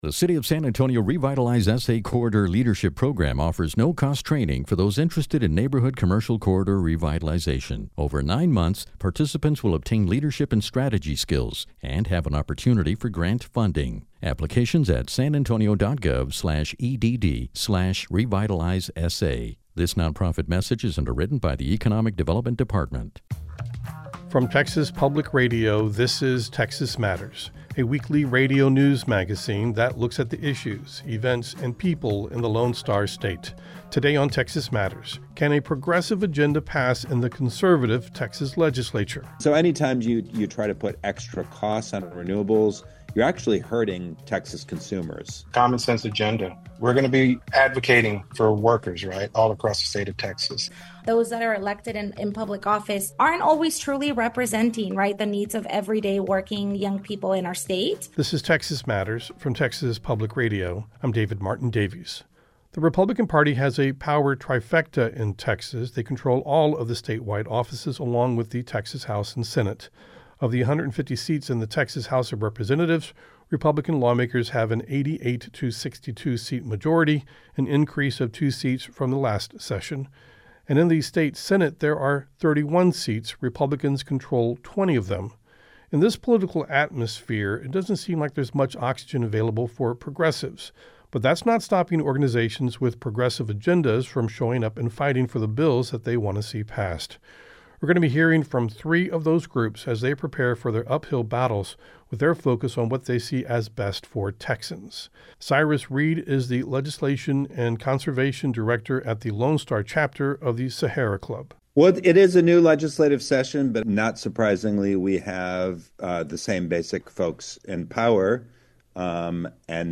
Texas Matters is a statewide news program that spends half an hour each week looking at the issues and culture of Texas.